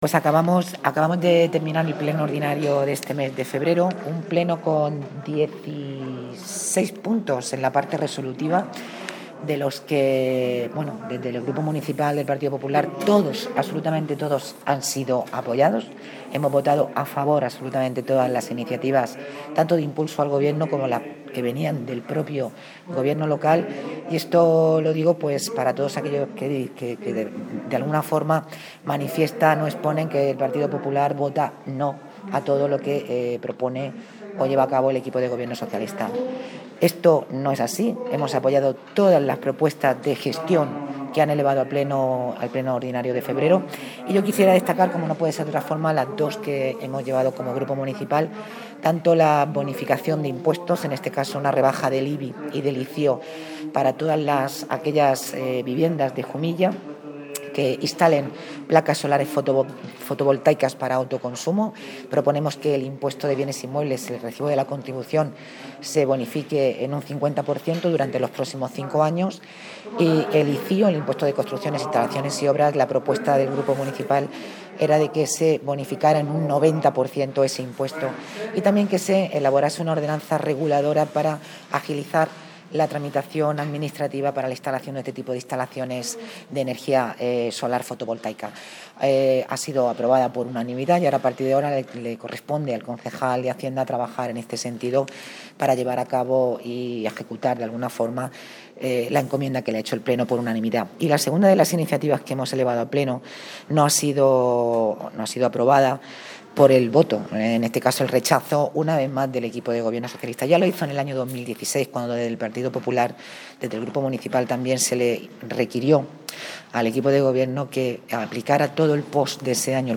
El Ayuntamiento de Jumilla propiciará la creación de la Red de Conjuntos Históricos-Artísticos de la Región de Murcia (01/03/2022) Download: Juan Gil - portavoz PSOE Seve González - portavoz PP Ginés P. Toral - portavoz Grupo Mixto top